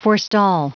Prononciation du mot forestall en anglais (fichier audio)
Prononciation du mot : forestall